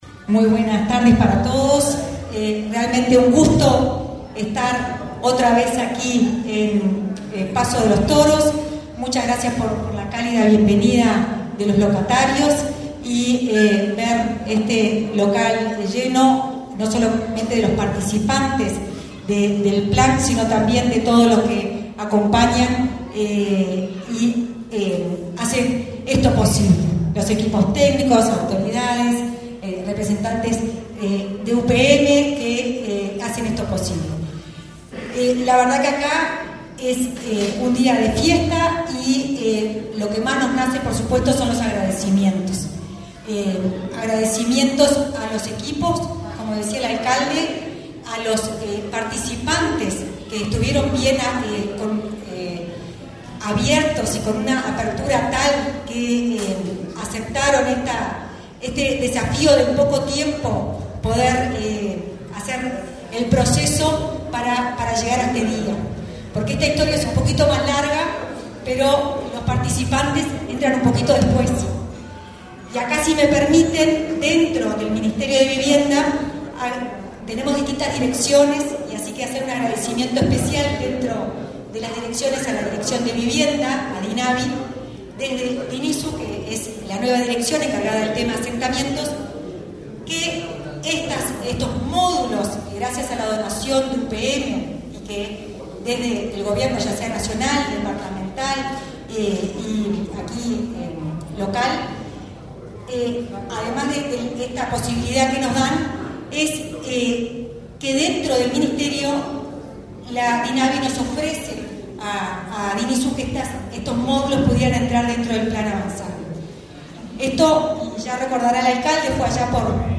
Acto de entrega de viviendas del Plan Avanzar en Tacuarembó
El Ministerio de Vivienda y Ordenamiento Territorial (MVOT) realizó, este 5 de febrero, la entrega de 48 viviendas del Plan Avanzar para el realojo de familias de los barrios La Balanza, Santa Isabel y Correntada, de la ciudad de Paso de los Toros, en el departamento de Tacuarembó. Participaron del evento el ministro, Raúl Lozano, y la directora de Integración Social y Urbana de la cartera, Florencia Arbeleche.